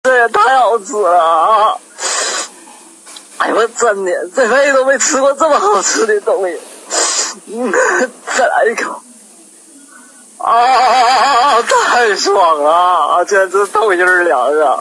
SFX啊这也太好吃了音效下载
SFX音效